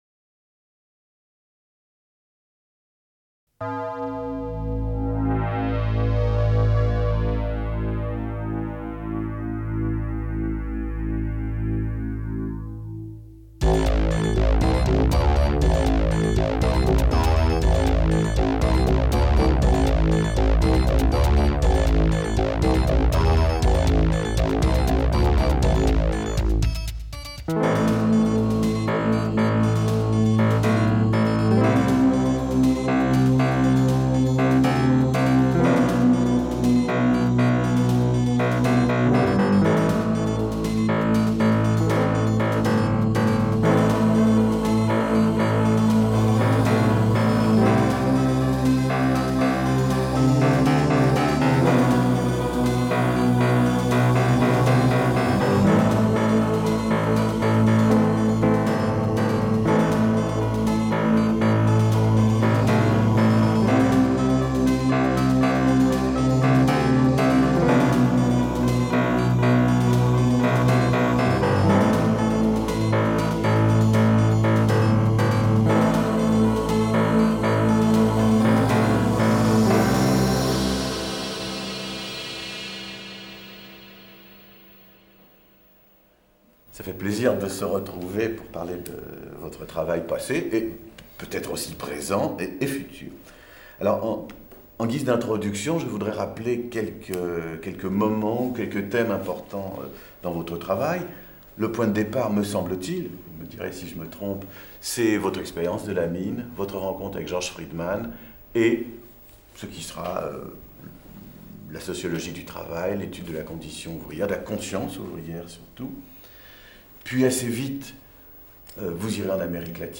Le parcours d'un sociologue : Entretien avec Alain Touraine | Canal U